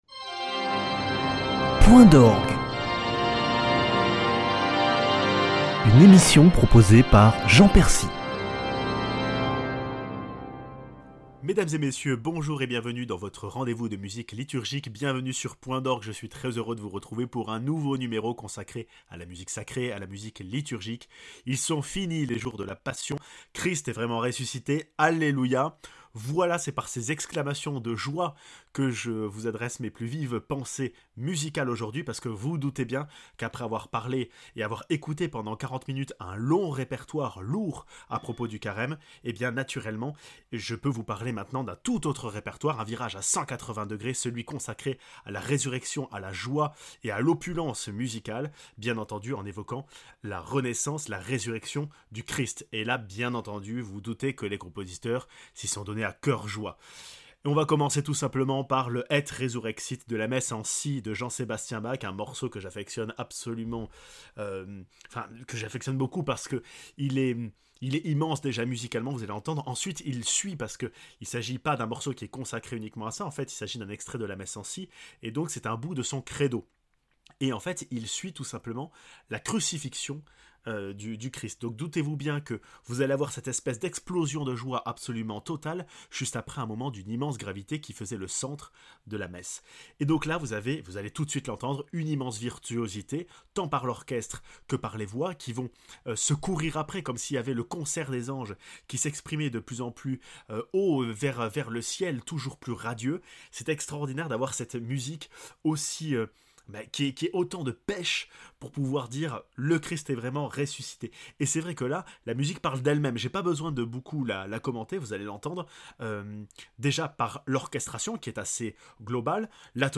Après quarante jours de carême et d’austérité musicale, la joie se manifeste enfin ! Et resurrexit de Bach - Victimae Pascali Laudes de Tournemire - Abendlied de Rheinberger